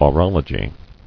[o·rol·o·gy]